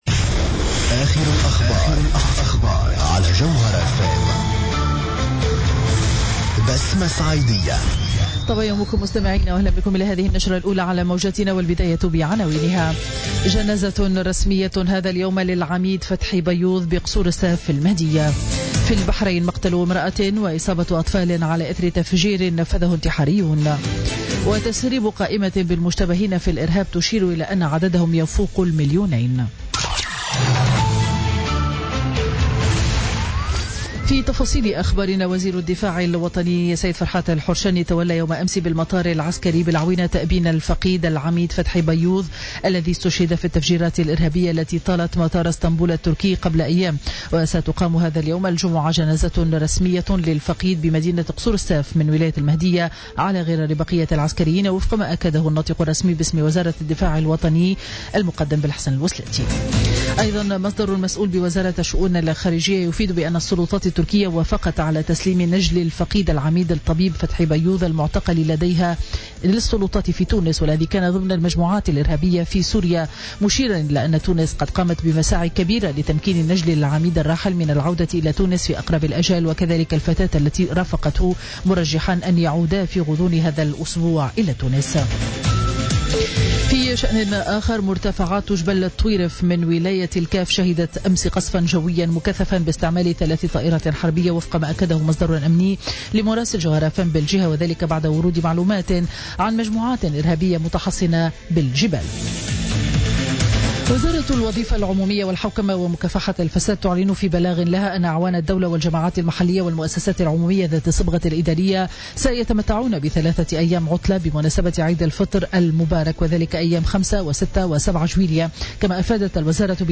نشرة أخبار السابعة صباحا ليوم الجمعة 1 جويلة 2016